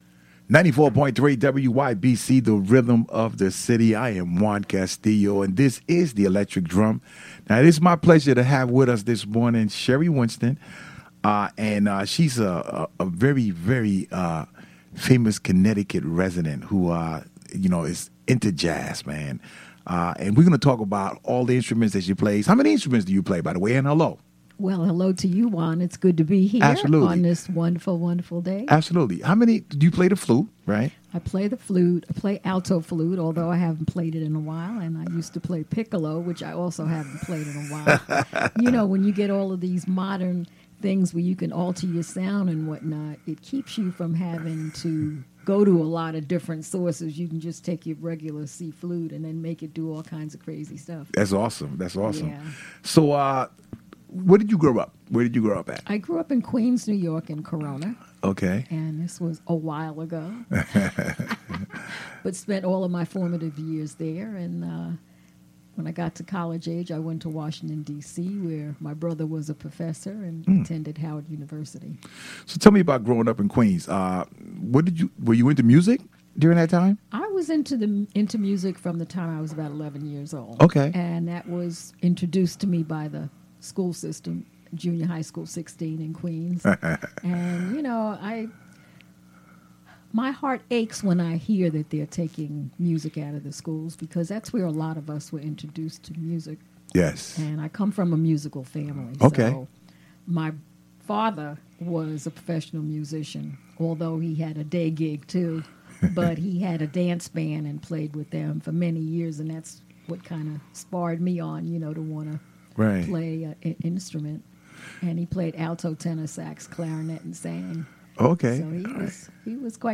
interviews national recording Jazz Artist Sherry Winston